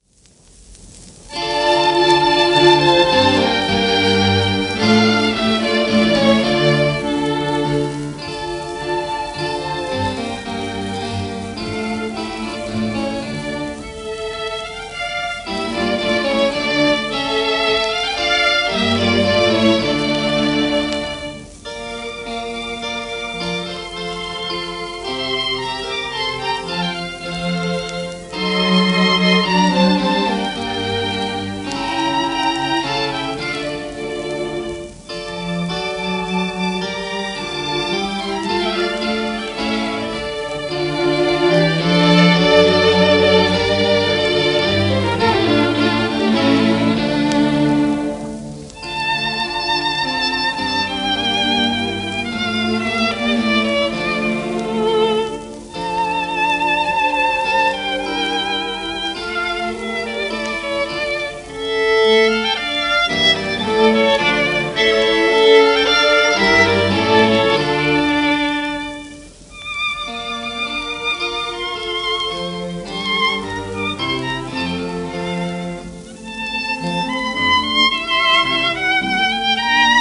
1937年録音